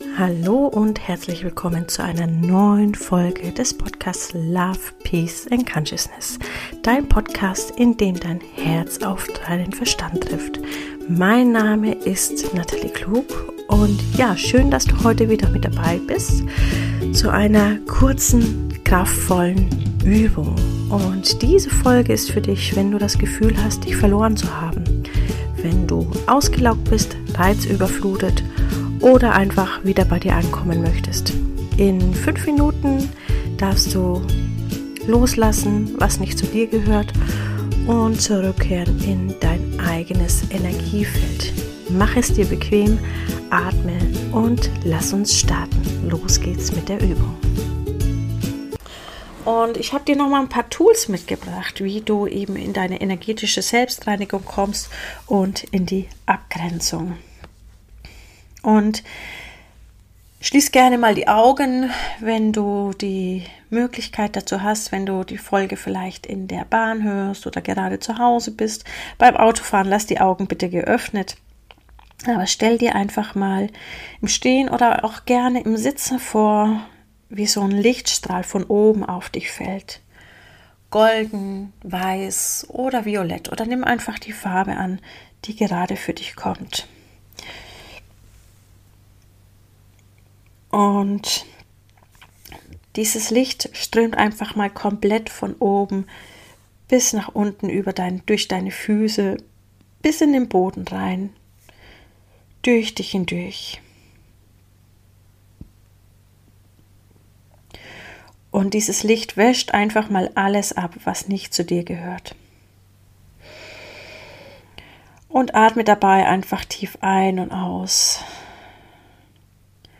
geführte 5 Minuten Übung für deine Kraft